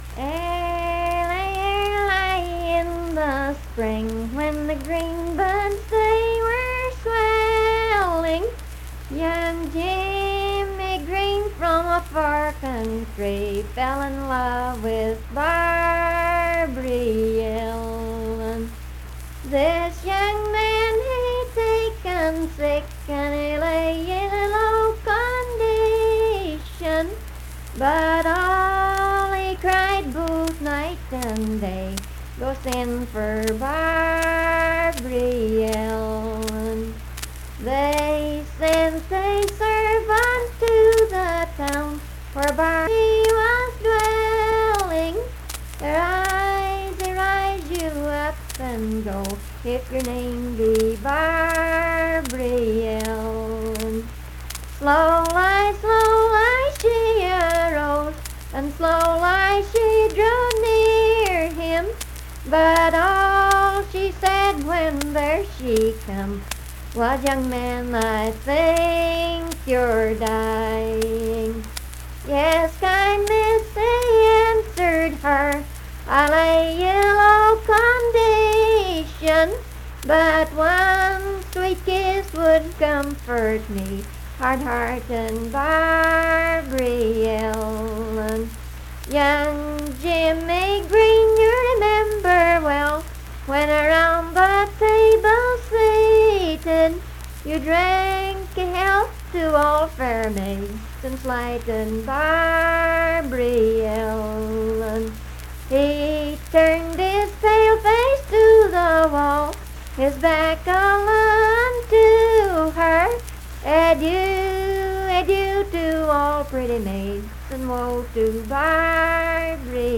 Unaccompanied vocal music
Performed in Strange Creek, Braxton, WV.
Voice (sung)